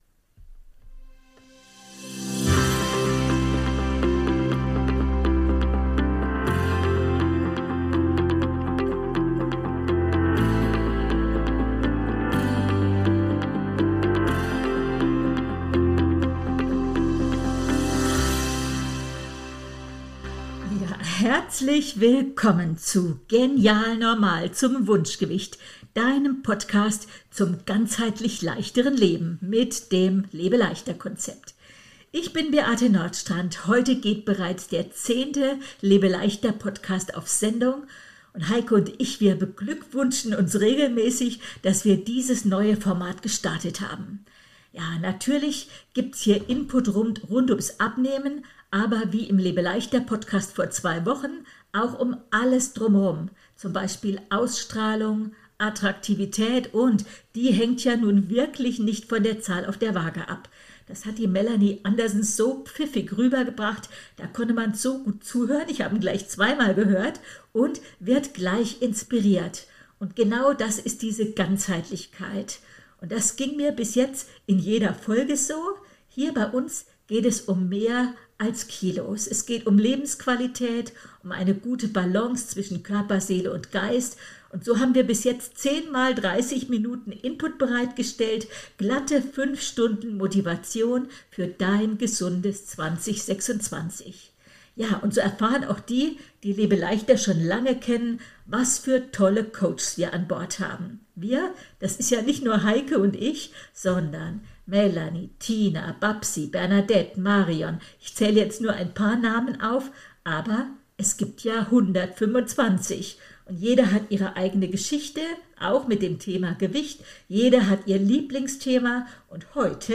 In unserem Interview schwärmt sie regelrecht von der Meisterleistung unseres Körpers, und zwar so wie er jetzt gerade ist. Sie erzählt von Zauberübungen, die ganz nebenbei wirken – beim Zähneputzen, Warten, Sitzen oder Kochen.